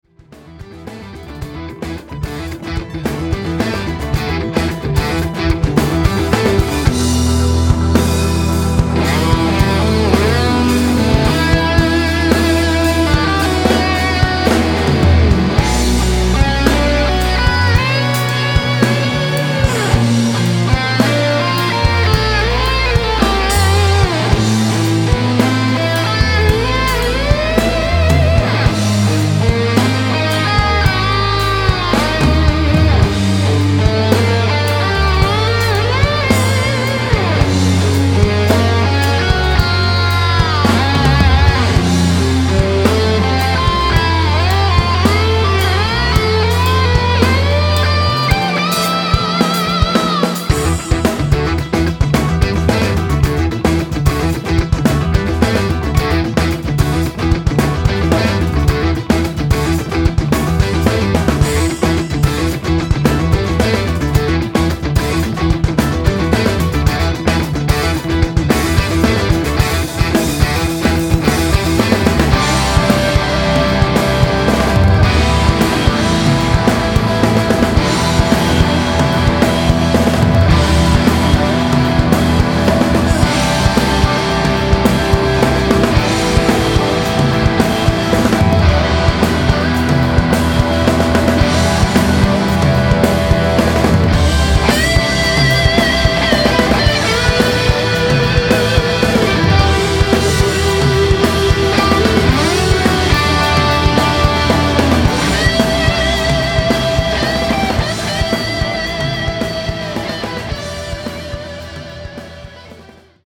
Инструментальный\гитарный рок - 1
Все живое (кроме секвенций), музон не мой, я записывал и сводил Поскольку релиза еще не произошло, могу выложить только фрагмент